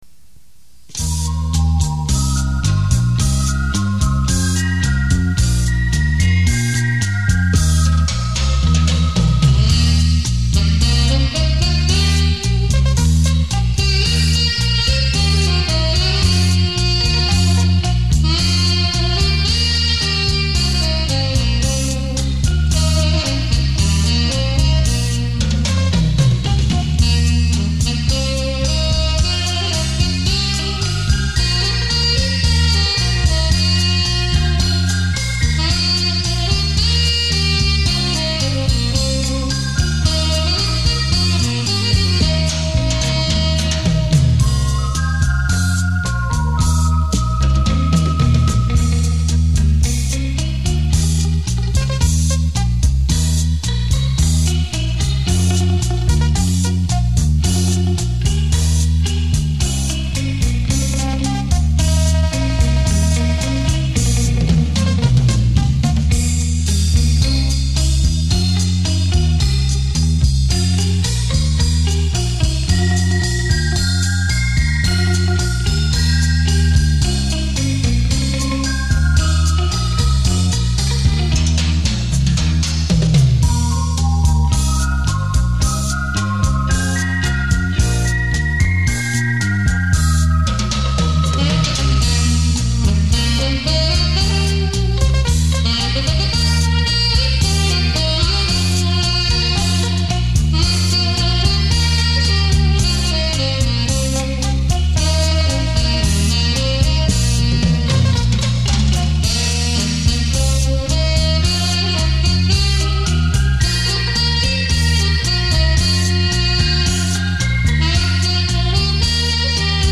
音乐介质:磁带转wav